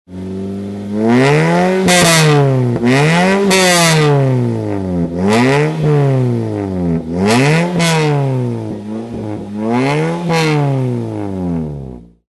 Звук разгоняющегося автомобиля